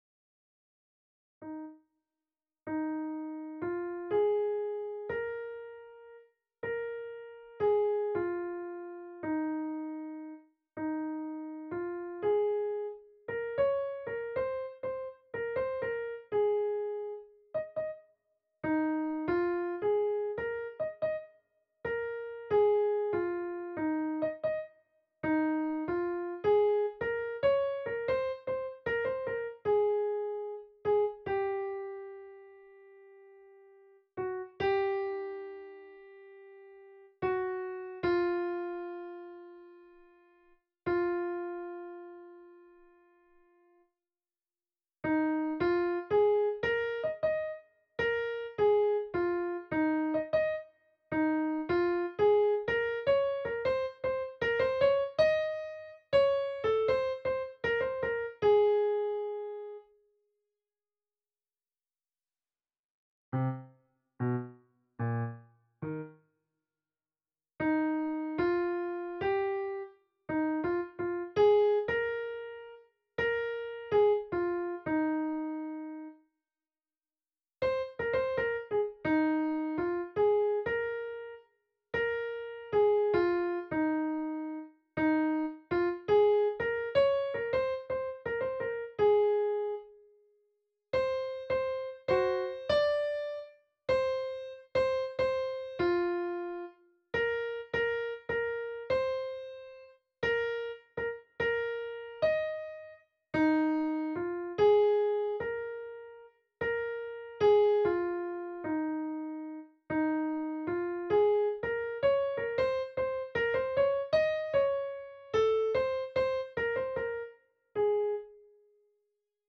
Øvefil for Sopran (wav)
I got rhythm ~ Sopran.WAV